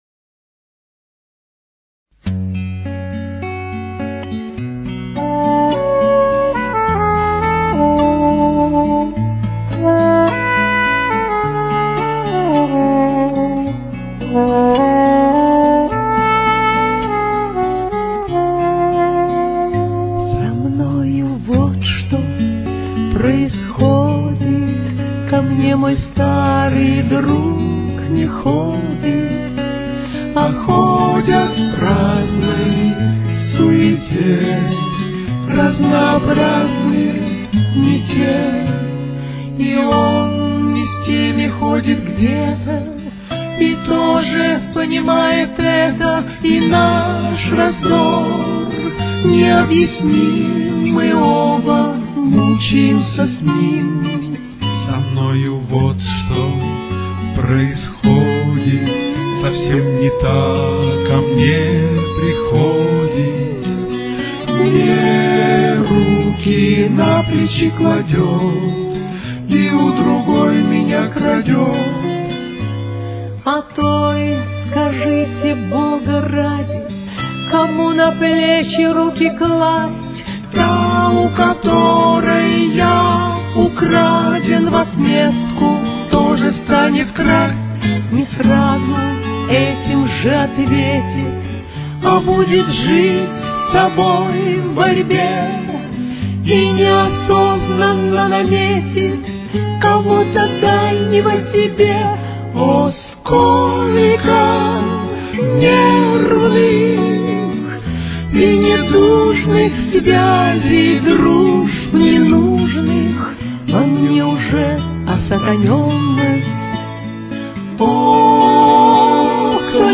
с очень низким качеством (16 – 32 кБит/с)
Фа-диез минор. Темп: 108.